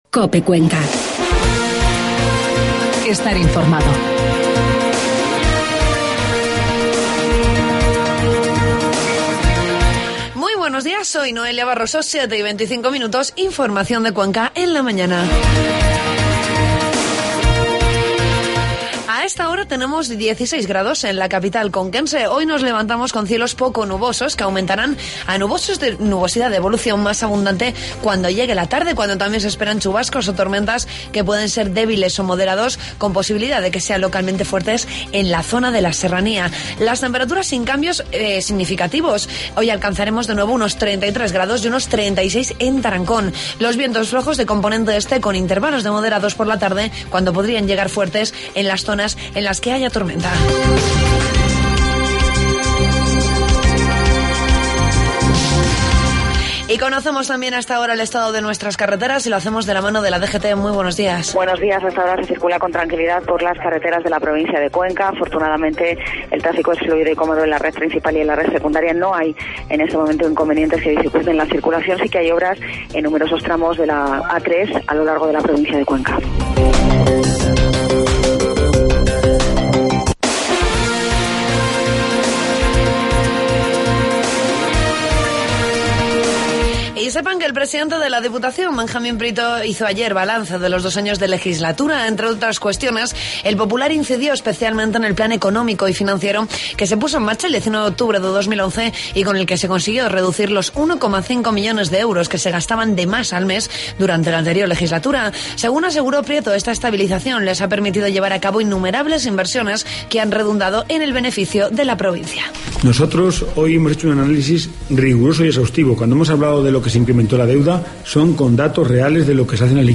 Informativo matinal 11 de julio